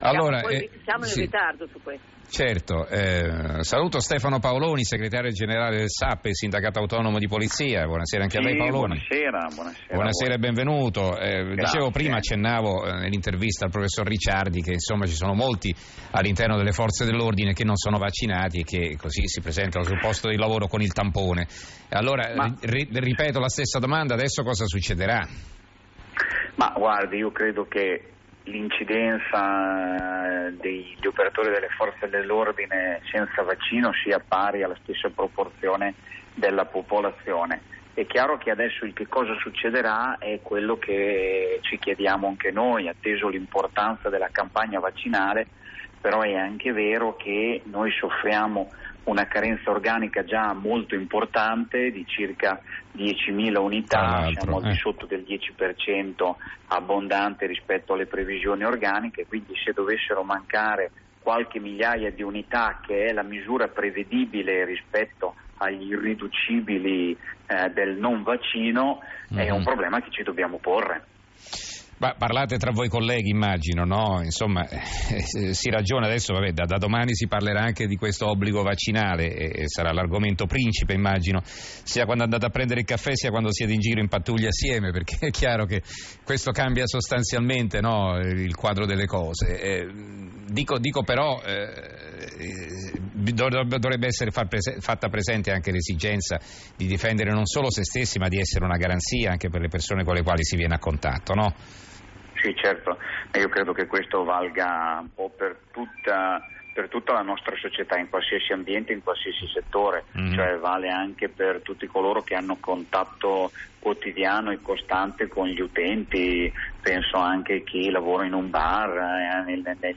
è intervenuto ai microfoni RAI di Radio1, nel corso dell’approfondimento della trasmissione Tra poco in Edicola.